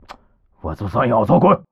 c02_11肉铺敲门_3_fx.wav